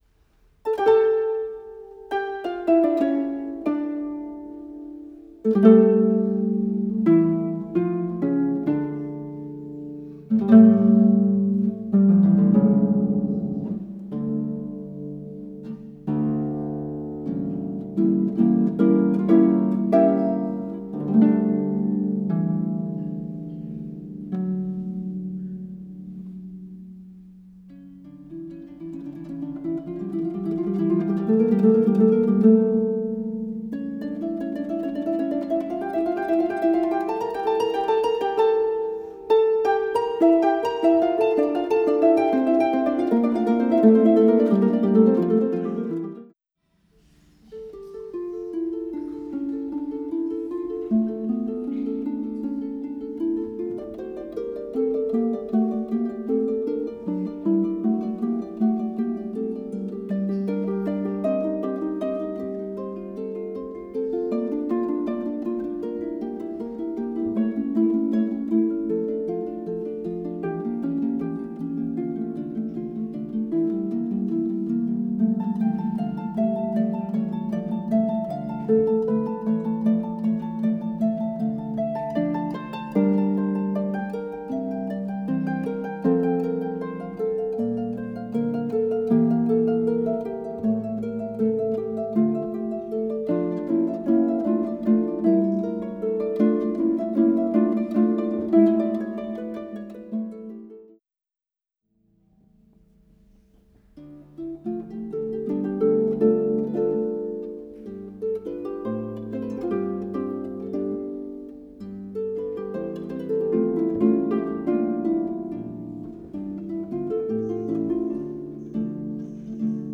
Live from Harp Recital Kaohsiung 2019
Harp
A pair of Schoeps MK2s as main pair